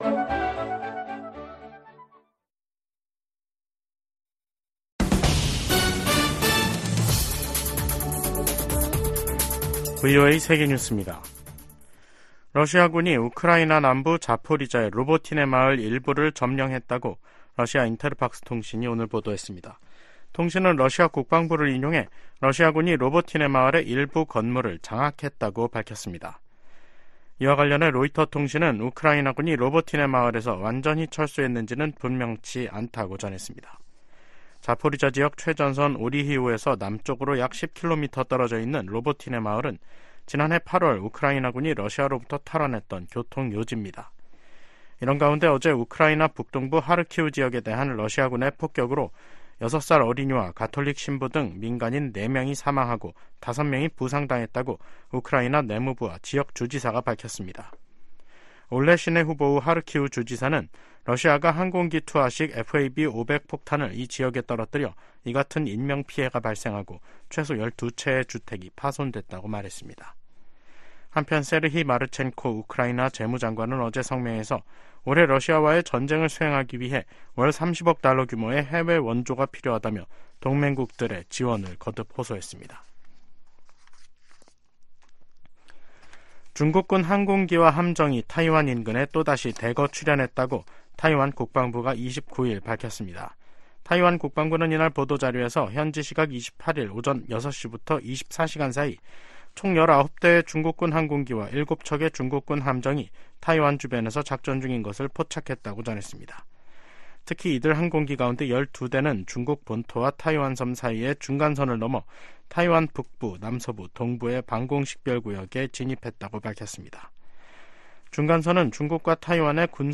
VOA 한국어 간판 뉴스 프로그램 '뉴스 투데이', 2024년 2월 29일 2부 방송입니다. 미국과 한국의 외교장관들이 워싱턴 D.C.에서 만나 세계의 거의 모든 도전에 공조하는 등 양국 협력이 어느 때보다 강력하다고 평가했습니다. 북한이 유엔 군축회의에서 국방력 강화 조치는 자위권 차원이라며 비난의 화살을 미국과 동맹에게 돌렸습니다. 세계 최대 식품 유통업체가 북한 강제 노동 동원 의혹을 받고 있는 중국 수산물 가공 업체 거래를 전격 중단했습니다.